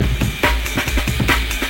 OVERDRIVE MUSIC - Boucle de Batteries - Drum Loops - Le meilleur des métronomes
Jungle 2
Straight / 141,426 / 1 mes
Jungle_2.mp3